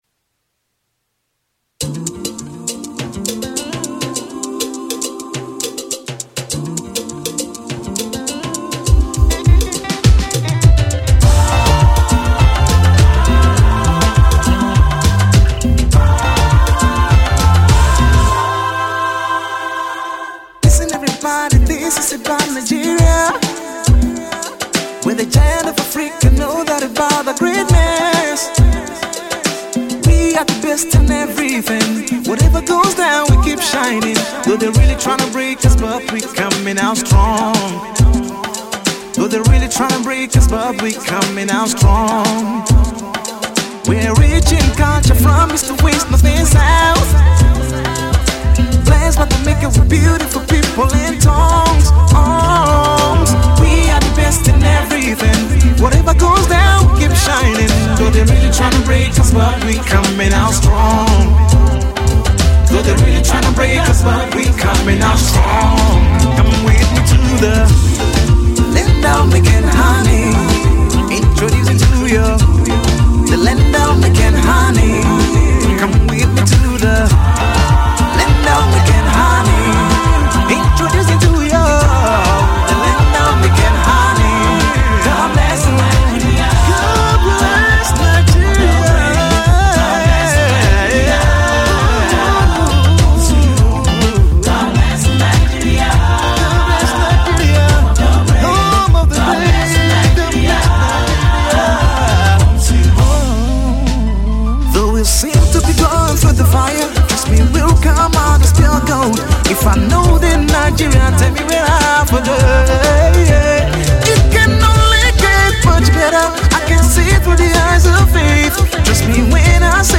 gospel/ inspirational